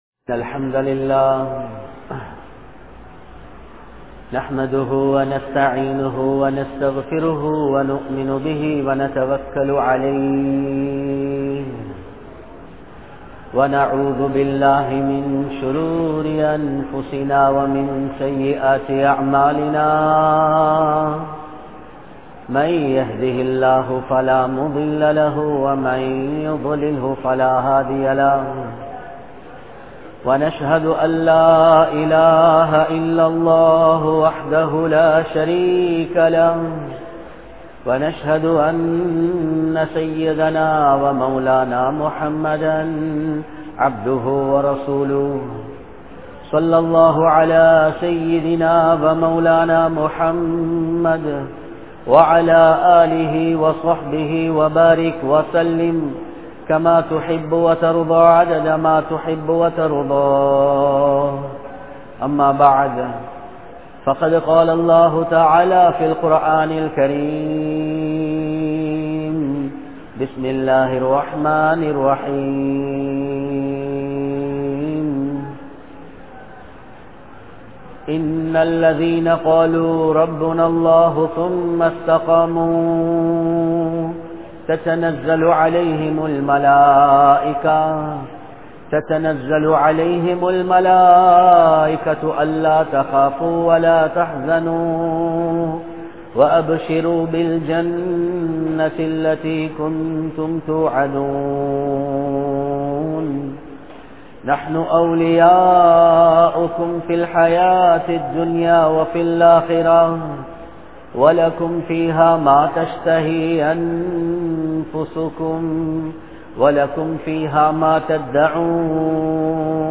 Paavaththin Irulhal (பாவத்தின் இருள்கள்) | Audio Bayans | All Ceylon Muslim Youth Community | Addalaichenai
Muhiyaddeen Grand Jumua Masjith